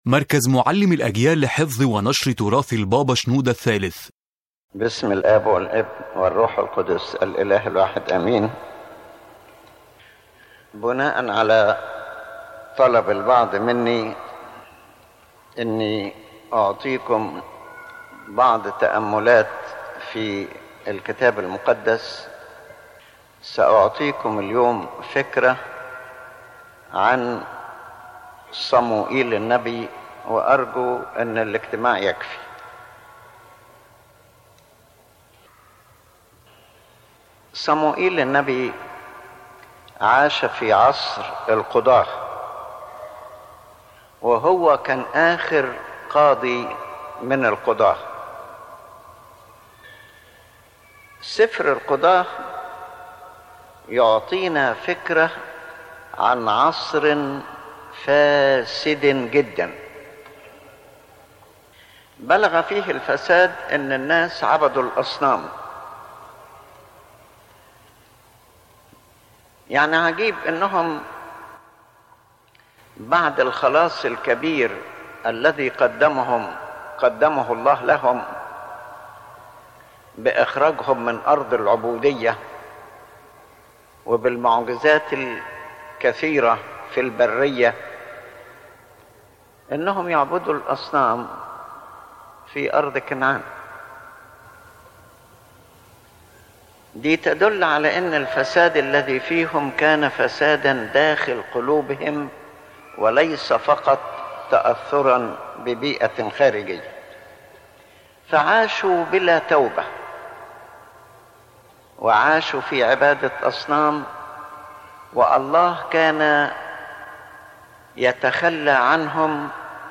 The lecture discusses the story of Prophet Samuel, who lived in a time characterized by spiritual corruption and idol worship.